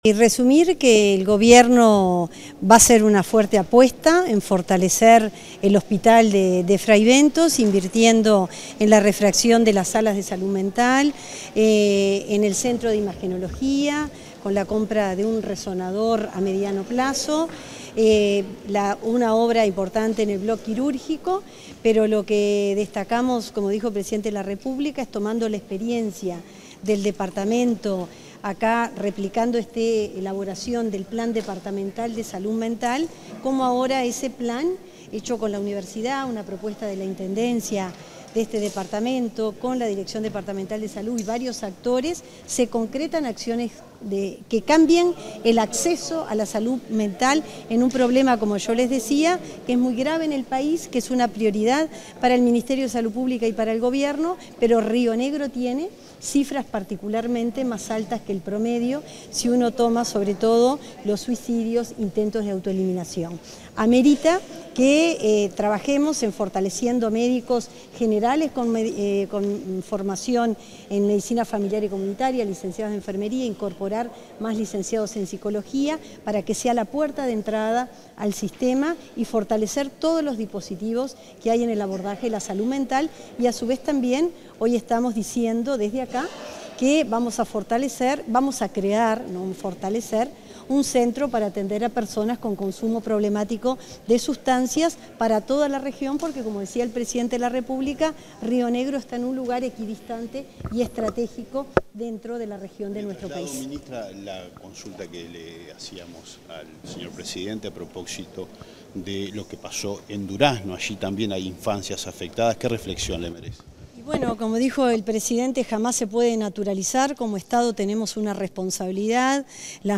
Declaraciones de la ministra de Salud Pública, Cristina Lustemberg
Declaraciones de la ministra de Salud Pública, Cristina Lustemberg 07/05/2025 Compartir Facebook X Copiar enlace WhatsApp LinkedIn La ministra de Salud Pública, Cristina Lustemberg, brindó declaraciones a la prensa, este miércoles 7, tras participar en la inauguración del Centro de Diálisis del Hospital de Clínicas.